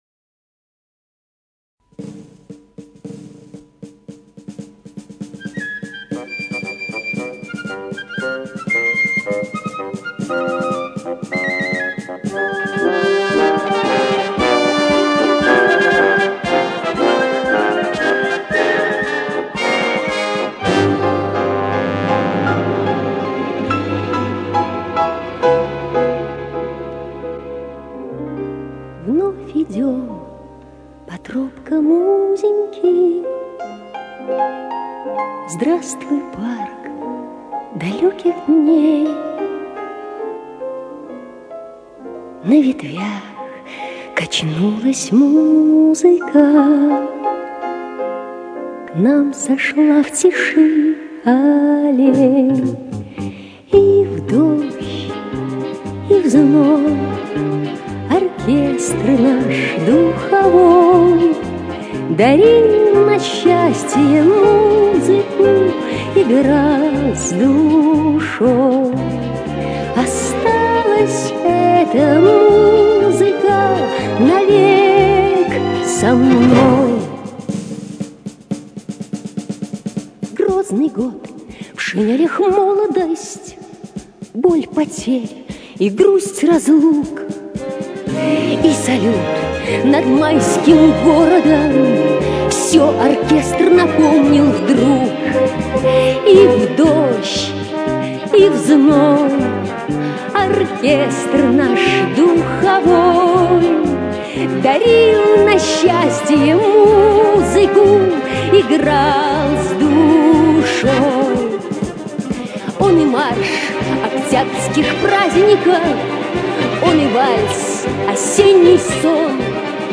Лирические песни 70-80-х годов